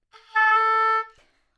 双簧管单音（弹得不好） " 双簧管 A4 攻击力差的舌头
描述：在巴塞罗那Universitat Pompeu Fabra音乐技术集团的goodsounds.org项目的背景下录制。单音乐器声音的Goodsound数据集。
Tag: 好声音 单注 多样本 Asharp4 纽曼-U87 双簧管